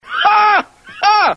Play Alf Laugh - SoundBoardGuy
alf-laugh.mp3